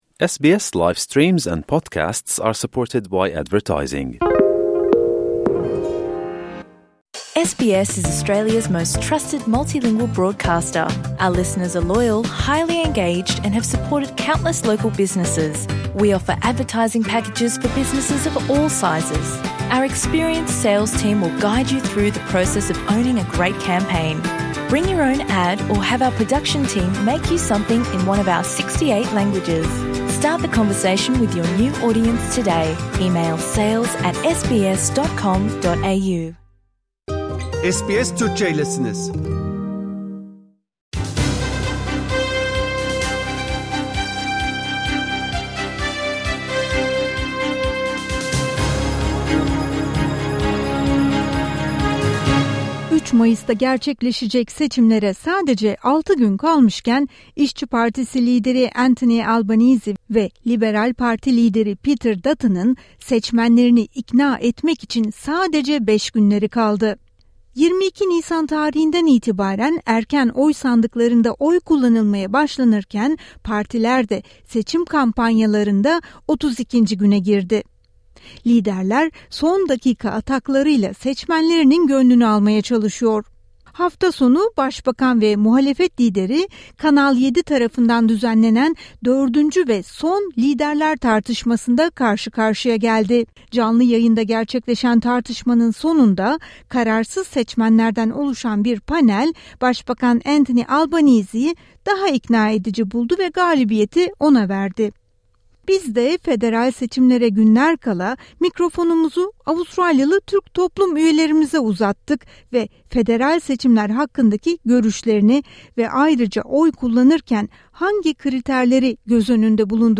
Biz de mikrofonumuzu Avustralya Türk toplum üyelerine uzattık ve oylarını hangi konuların, neden etkileyeceğini sorduk.